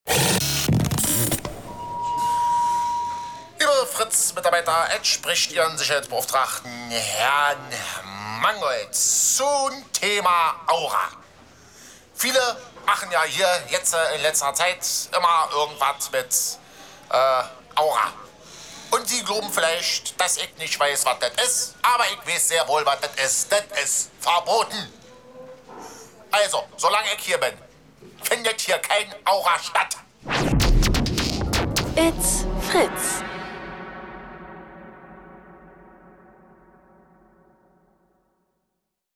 Fritz Sound Meme Jingle
Die MP3 ist unbearbeitet und wurde ursprünglich von Fritz unter der CC-Lizenz hier veröffentlicht (Namensnennung, keine kommerzielle Nutzung, keine Bearbeitung).